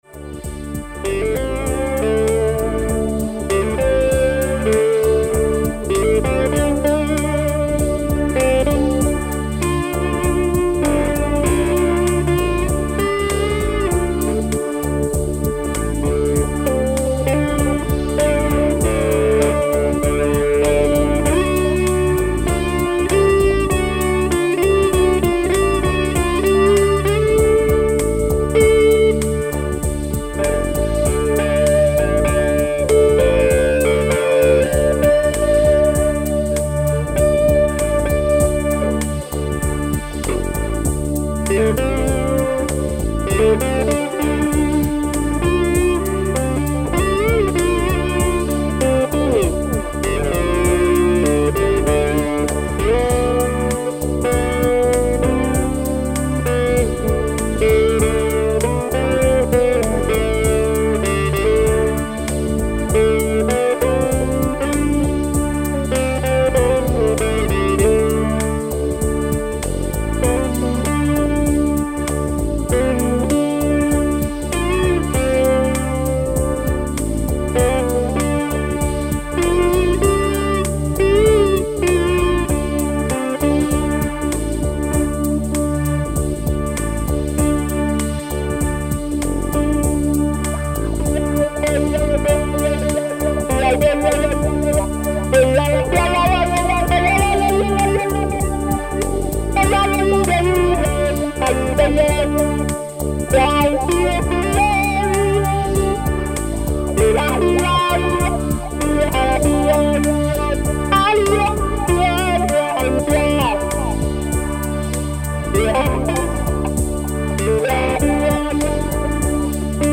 Tempo: 50 bpm / Datum: 06.04.2017